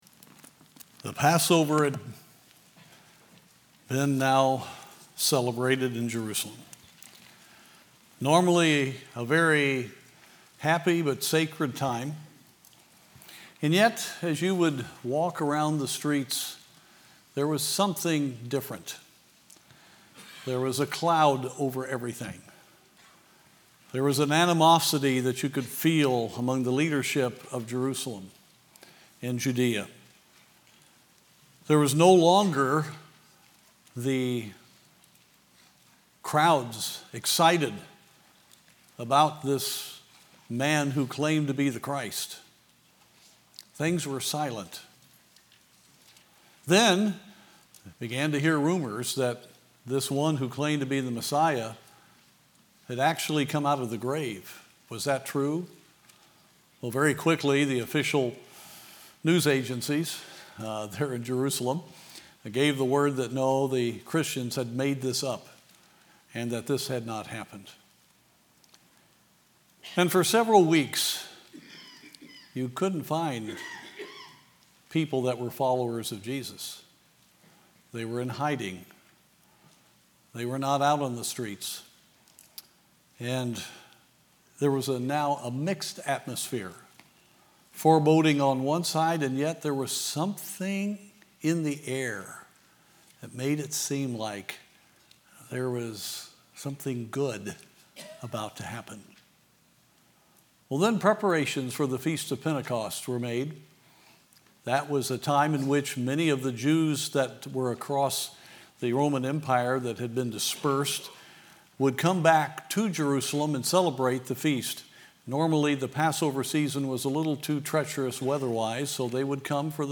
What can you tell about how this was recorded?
Sunday evening message from the pulpit of Falls Baptist Church